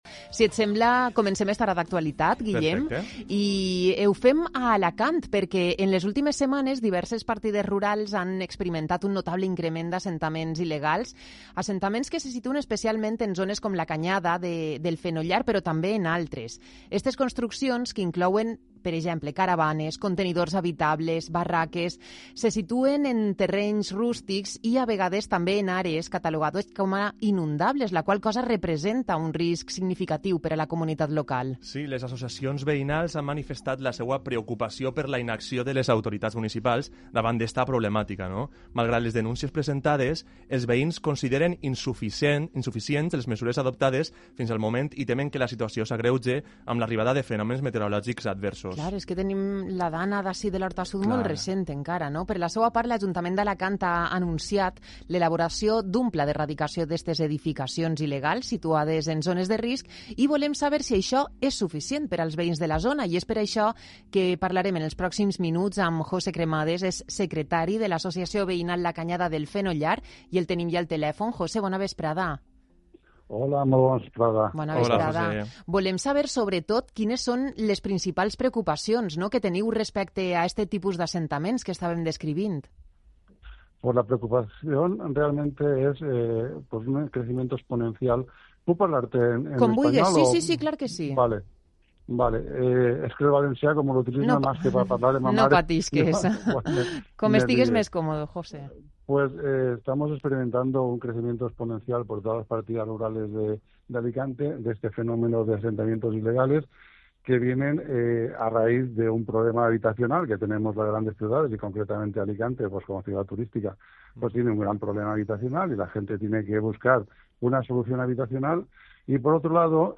ENTREVISTA-A-PIUNT.mp3